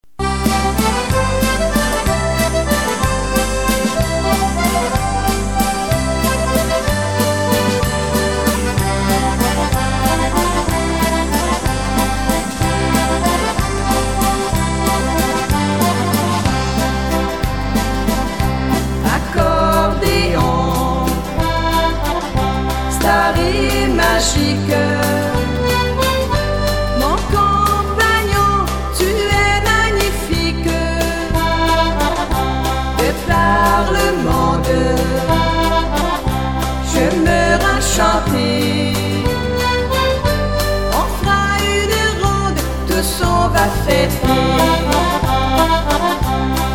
AU SON DE L'ACCORDÉON...C'EST CERTAIN !!!
UNE INVITATION A LA DANSE !